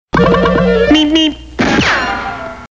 road-runner_24875.mp3